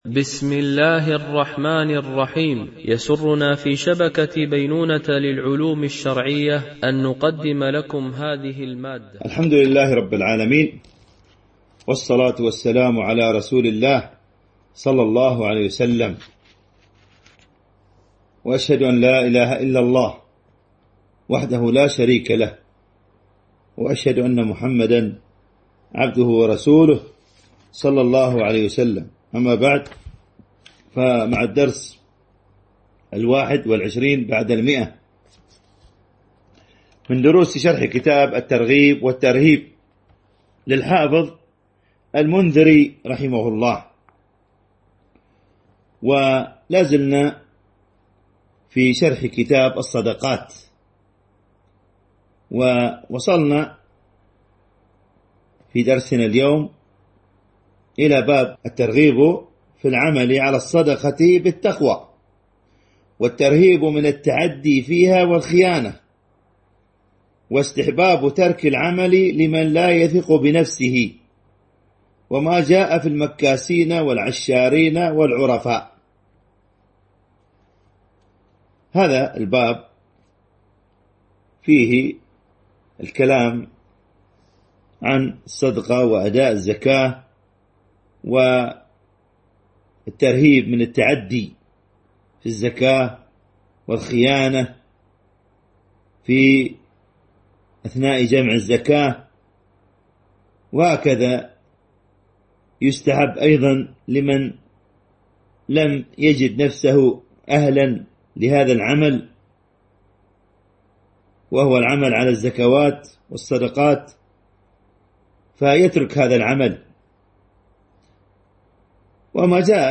) الألبوم: شبكة بينونة للعلوم الشرعية التتبع: 121 المدة: 27:09 دقائق (6.23 م.بايت) التنسيق: MP3 Mono 22kHz 32Kbps (CBR)